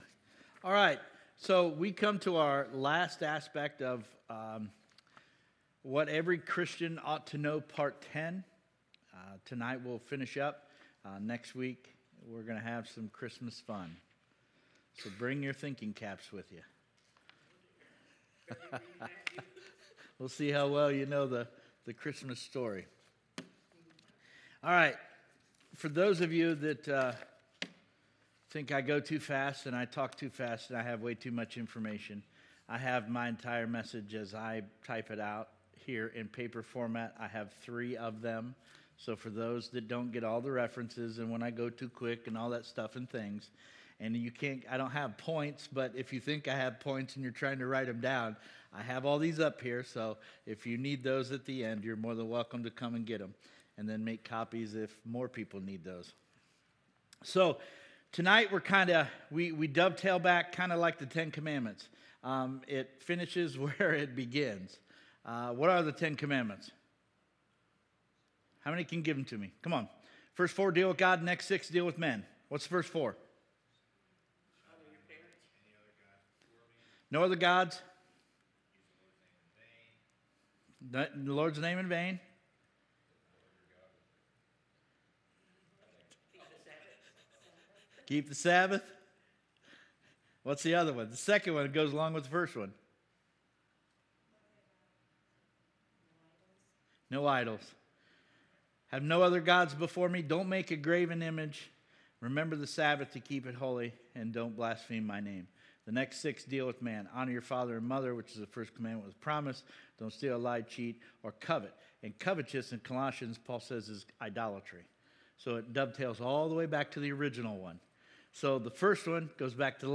Sermons Archive -